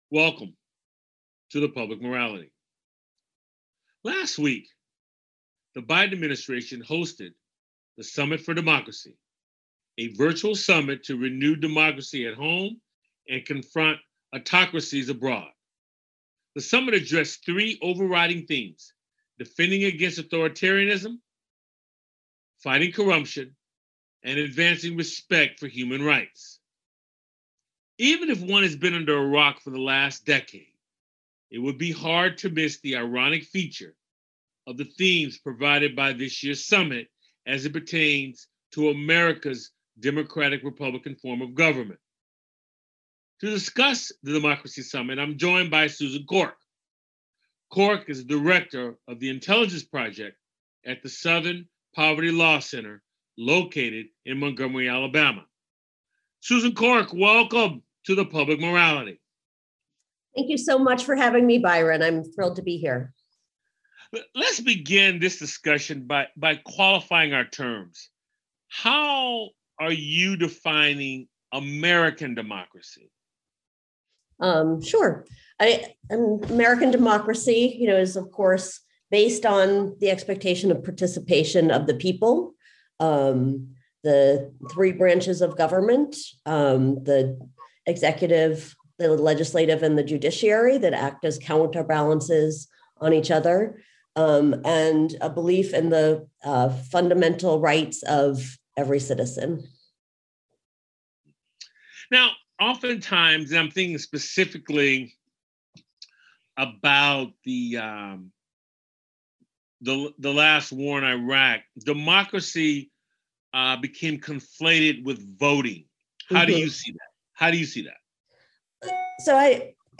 It's a weekly conversation with guest scholars, artists, activists, scientists, philosophers, and newsmakers who focus on the Declaration of Independence, the Constitution and the Emancipation Proclamation as its backdrop for dialogue on issues important to our lives. The show airs on 90.5FM WSNC and through our Website streaming Tuesdays at 7:00p.